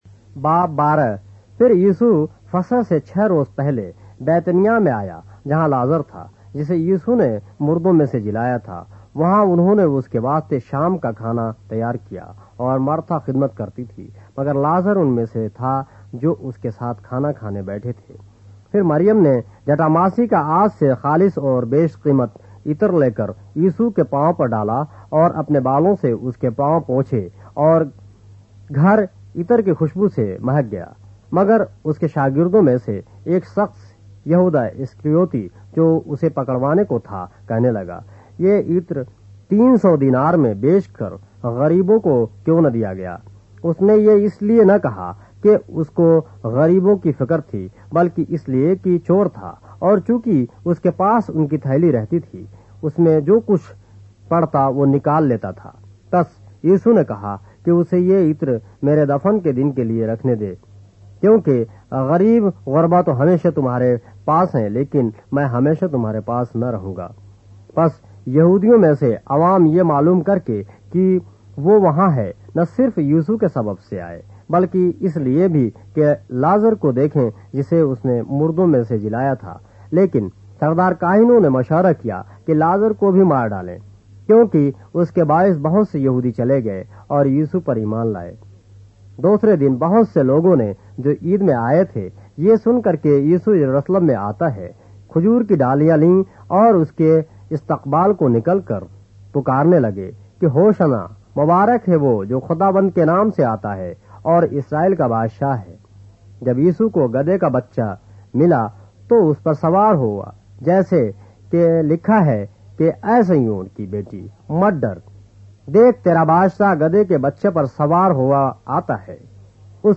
اردو بائبل کے باب - آڈیو روایت کے ساتھ - John, chapter 12 of the Holy Bible in Urdu